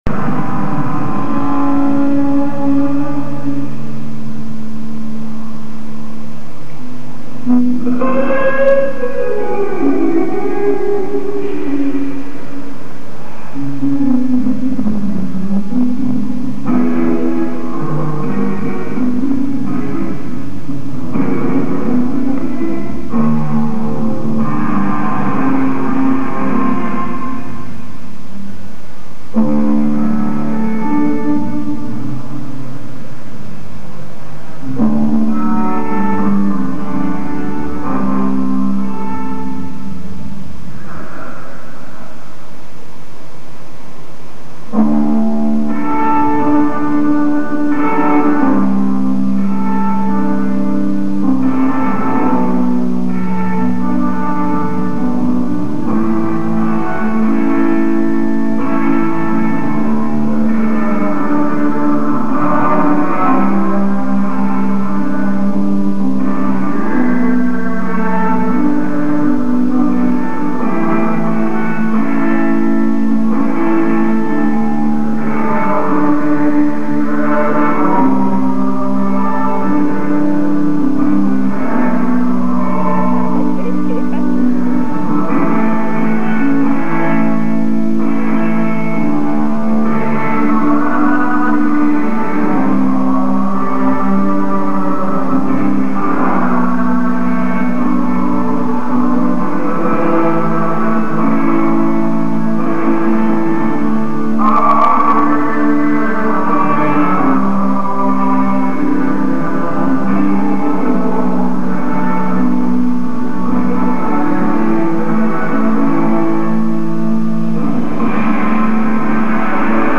06/14/00 - Peagas Arena: Prague, Czech Republic [135m]
(both from soundcheck)